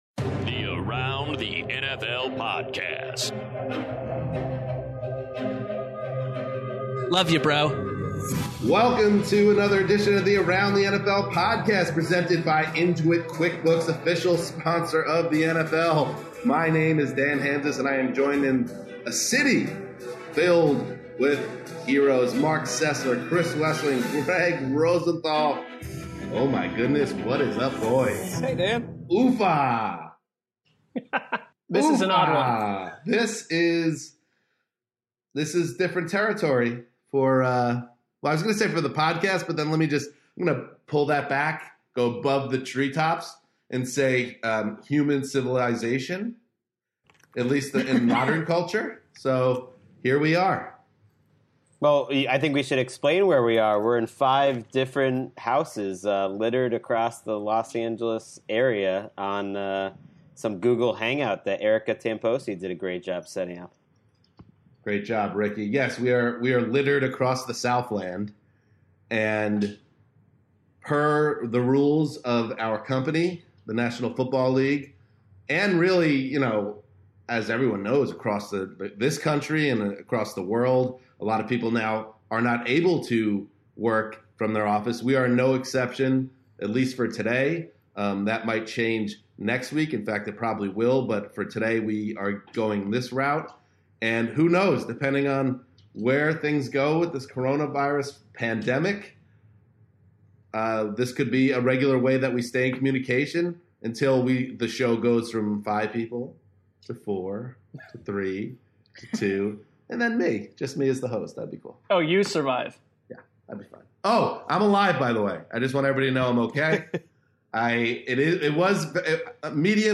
A google hangout full of heroes